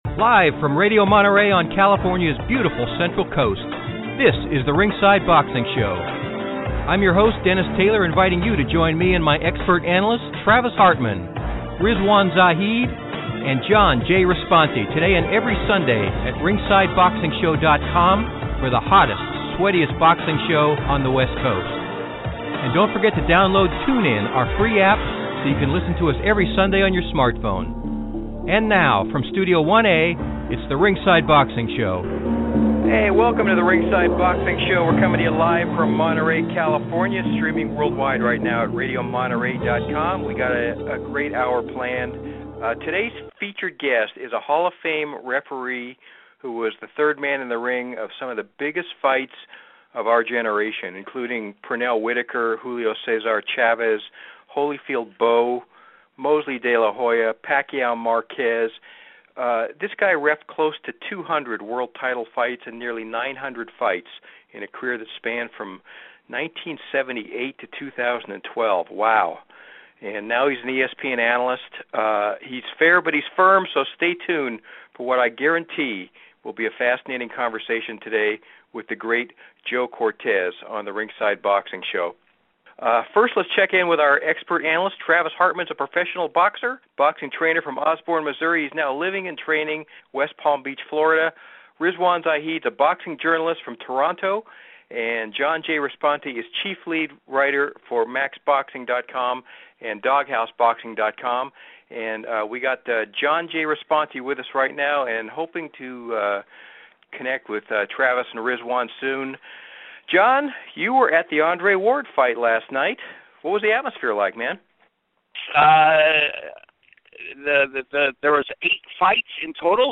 The Hall of Fame referee joined us for his second appearance on The Ringside Boxing Show to tell Easter Sunday stories about the biggest fights of multiple eras -- Whitaker-Chavez, Holyfield-Bowe, Mosley-De La Hoya, Duran-Barkley, Barrera-Hamed -- all of which he officiated.